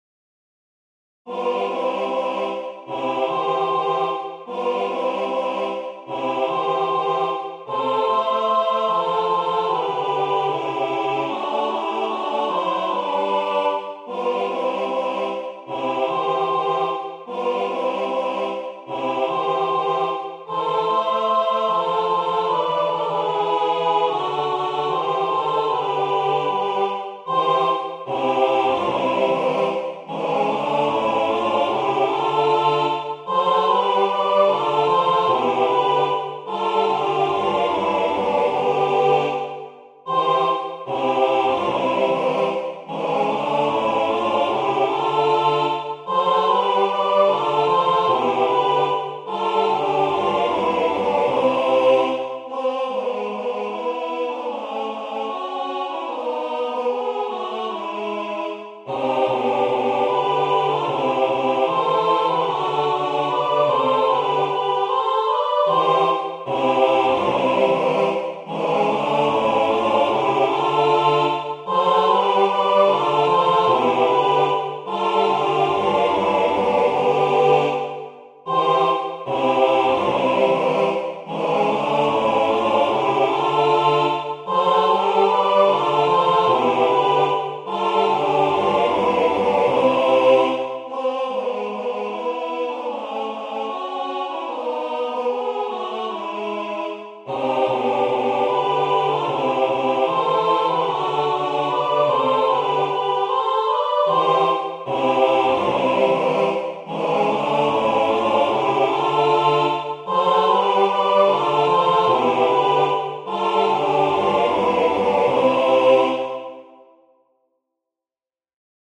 Nuty na orkiestrę rozrywkową i wokal.